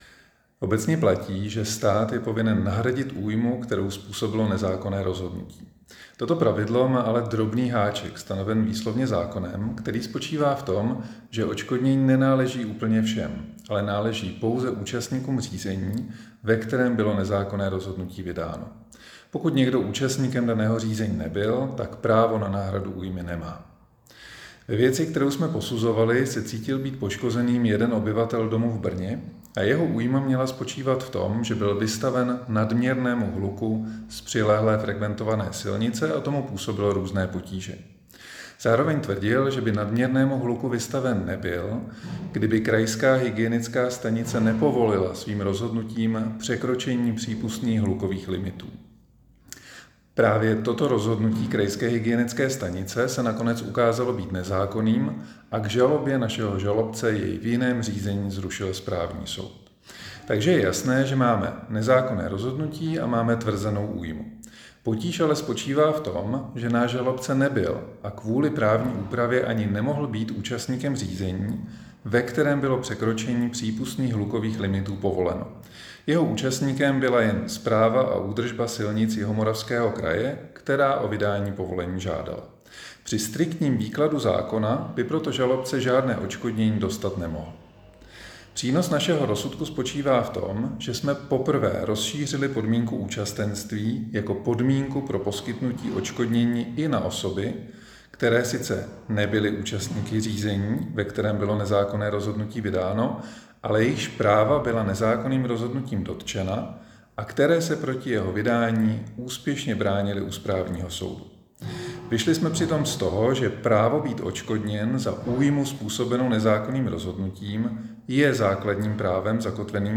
Vyjádření předsedy senátu JUDr. Pavla Simona k otázce nového výkladu aktivní věcné legitimace osob, které se domáhají po státu odškodnění za nemajetkovou újmu způsobenou z důvodu nezákonného rozhodnutí:
Vyjádření JUDr. PAVLA SIMONA